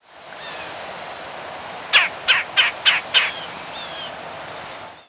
Red-bellied Woodpecker Melanerpes carolinus
Flight call description A husky "gher", often given in a series of two or more.
Fig.1. New Jersey September 27, 2000 (MO).
Bird in flight with Blue Jay calling in the background.